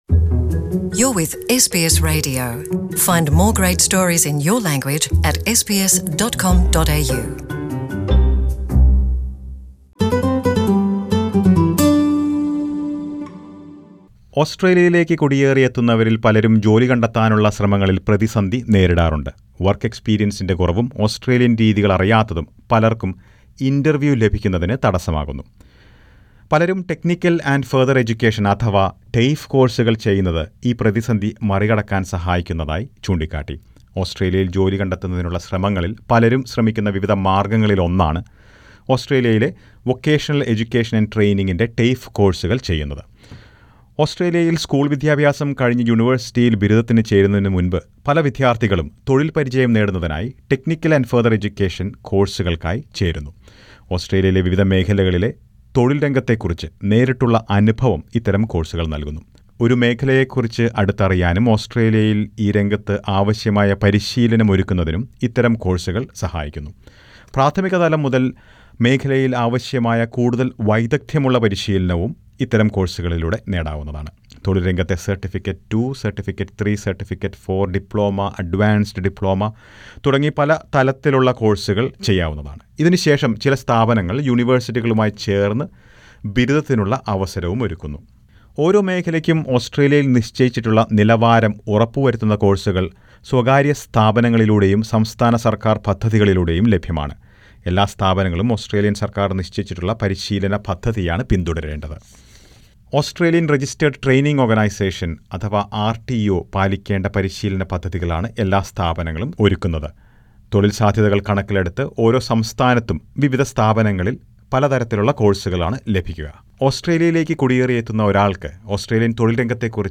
ഓസ്‌ട്രേലിയയിൽ പ്രവൃത്തി പരിചയത്തിന്റെ കുറവും ഇംഗ്ലീഷ് ഭാഷാ പ്രാവിണ്യത്തിന്റെ കുറവുമാണ് മിക്കവർക്കും ജോലി കണ്ടെത്താനുള്ള ശ്രമങ്ങളിൽ പ്രതിസന്ധിയാകുന്നത്. എന്നാൽ ഈ പ്രതിസന്ധികൾ മറികടക്കാൻ പലരും TAFE കോഴ്സുകൾ പഠിക്കുന്നു. TAFE കോഴ്സുകൾ ചെയ്തത് വഴി ജോലികണ്ടെത്താൻ കഴിഞ്ഞ ചില മലയാളികളുടെ അനുഭവങ്ങൾ ഉൾപ്പെടുത്തിയ റിപ്പോർട്ട് കേൾക്കാം മുകളിലെ പ്ലേയറിൽ നിന്ന്.